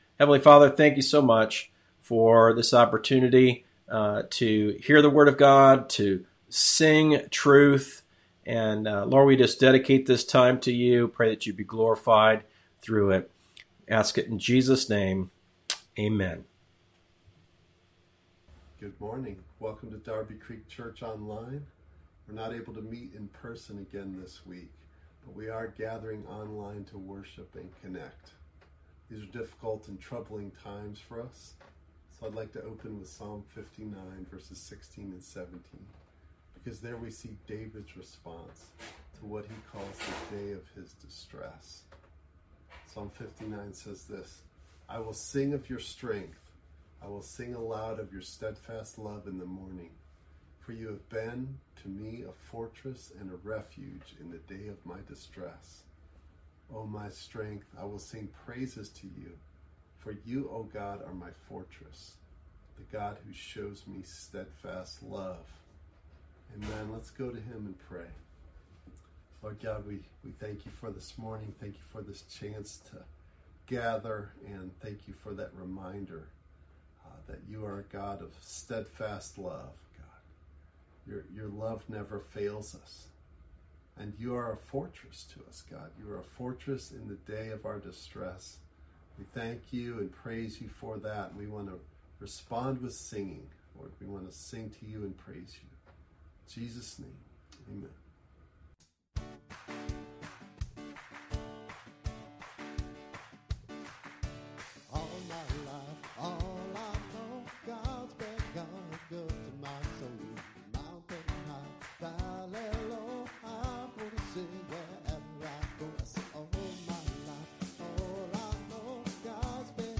From Series: "Stand Alone Sermons"